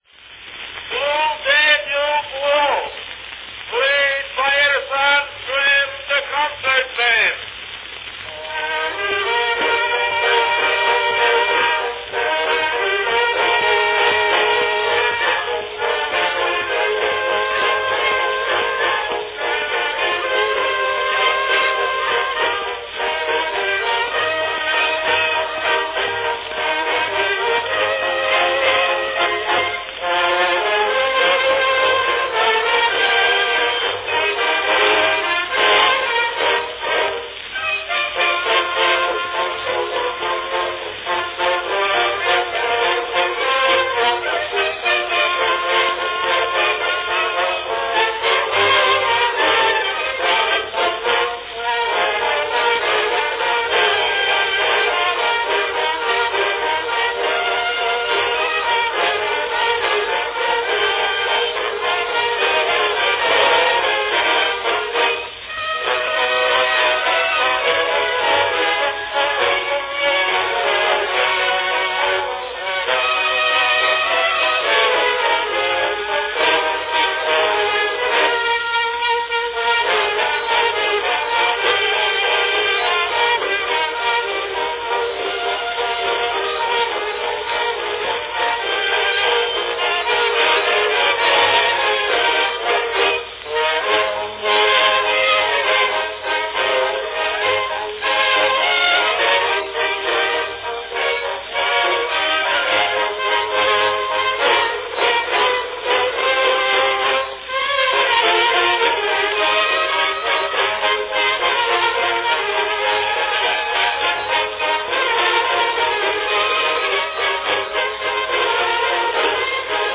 Cylinder of the Month
Category Band
Performed by Edison Grand Concert Band
Announcement "Blue Danube Waltz, played by Edison's Grand Concert Band."
The piece normally starts in A-major but, being that the Edison Concert Band is a wind band, the key here is in Bb.